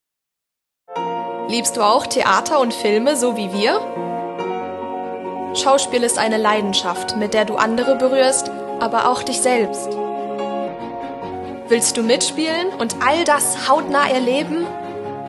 Werbetrailer